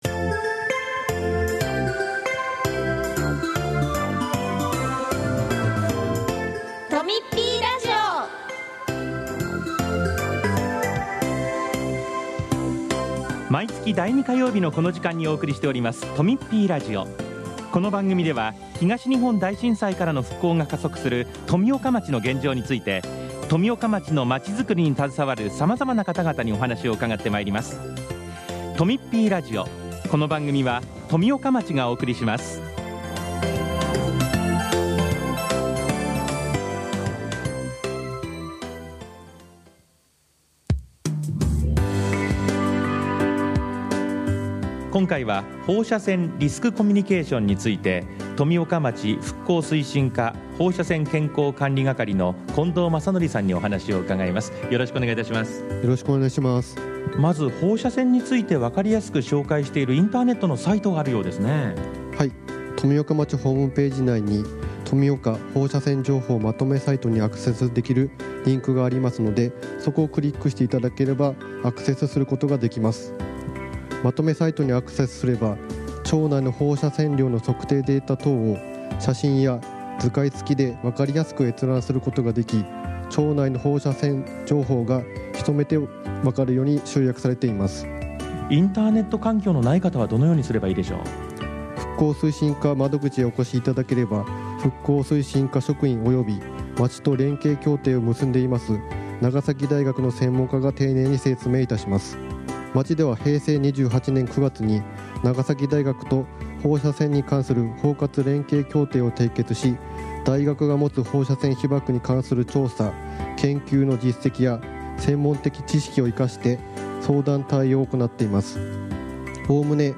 今回は、復興推進課の職員による「放射線リスクコミュニケーション活動」についてのインタビューです。その他、町からのお知らせもあります。